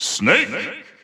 The announcer saying Snake's name in English and Japanese releases of Super Smash Bros. Ultimate.
Snake_English_Announcer_SSBU.wav